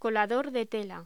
Locución: Colador de tela
voz